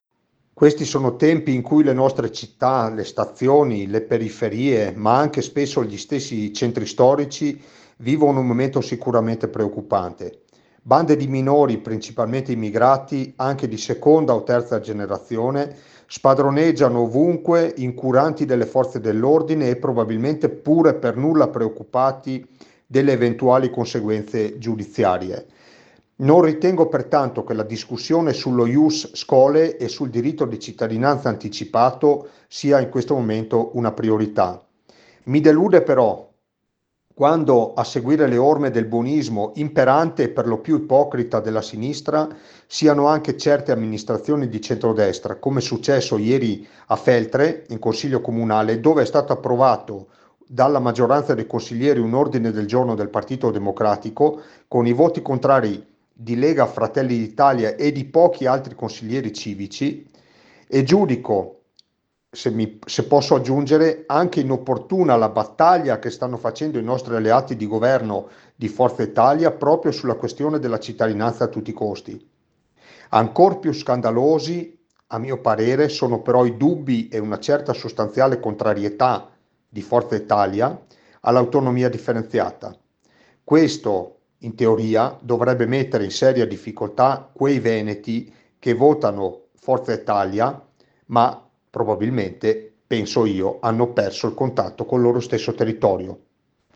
Intervistati
(AL TELEFONO)